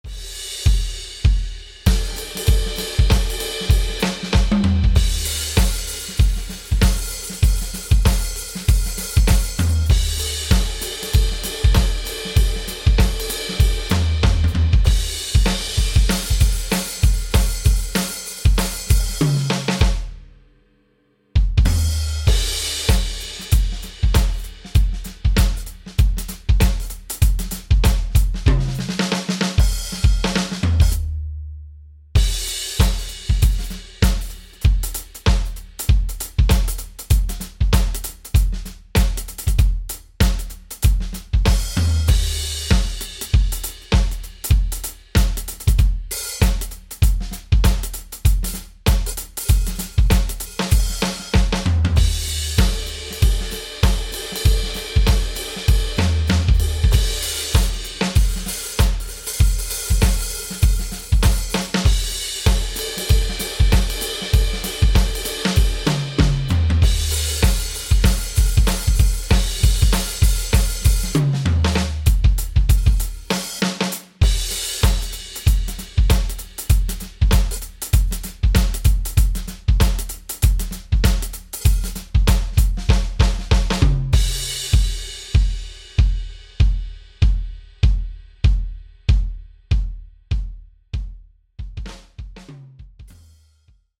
DW Soundworks 是 DW（Drum Workshop）与 Roland 联合开发的旗舰级虚拟鼓音源插件，主打真实原声鼓采样、多麦克风混音、深度自定义与可扩展音色库，是追求顶级原声鼓质感的制作人首选。